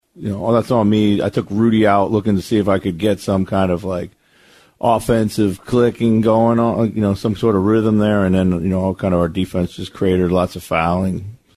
Wolves head coach Chris Finch on what contributed to the Lakers third quarter surge.